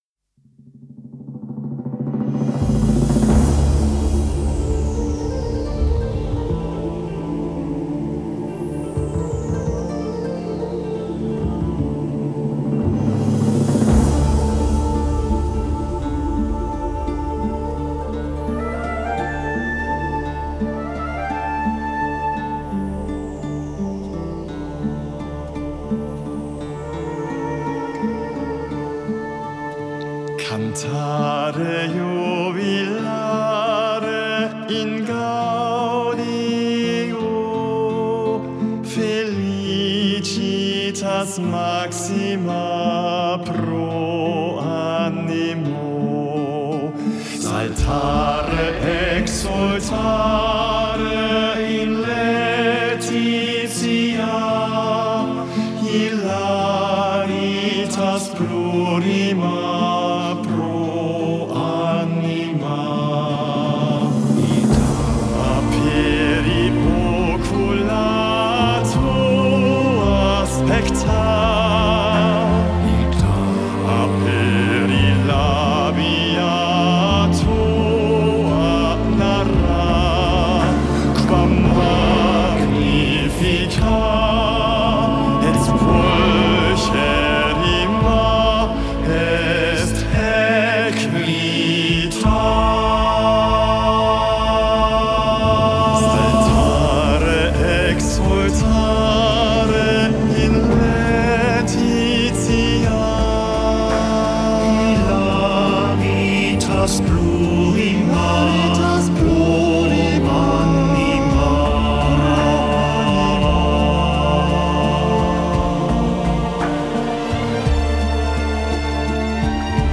Genre: Gregorian chant / Pop-Mystic / Choral
/ Neo-Classical / New Age